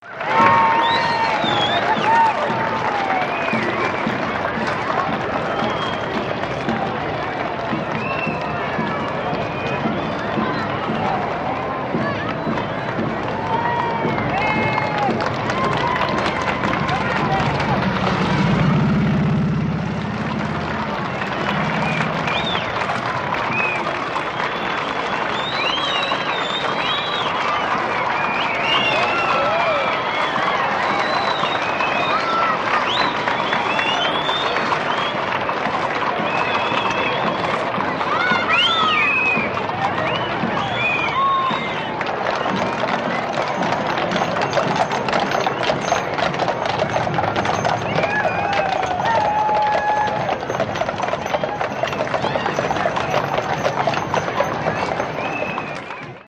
Parades
Crowd Applause And Whistles For Parade Approaching, Police Motorcycle And Horse Bys With Crowd